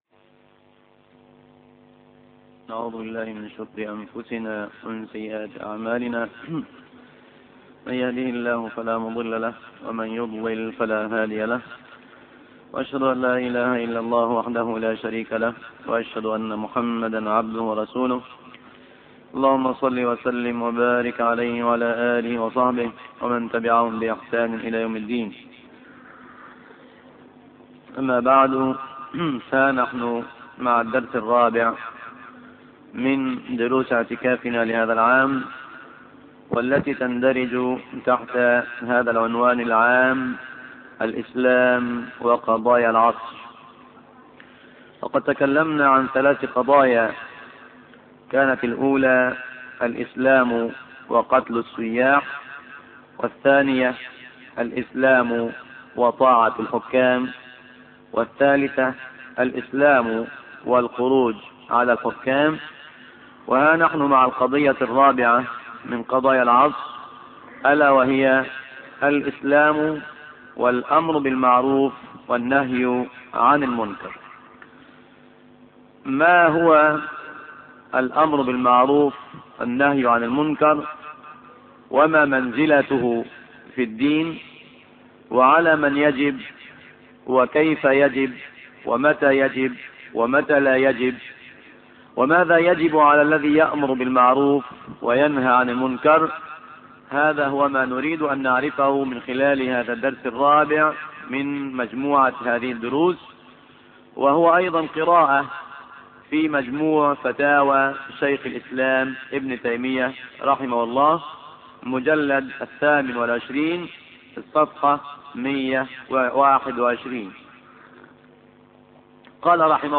عنوان المادة الدرس الرابع : الإسلام والأمر بالمعروف والنهى عن المنكر تاريخ التحميل الأثنين 1 ديسمبر 2008 مـ حجم المادة 18.88 ميجا بايت عدد الزيارات 1,348 زيارة عدد مرات الحفظ 452 مرة إستماع المادة حفظ المادة اضف تعليقك أرسل لصديق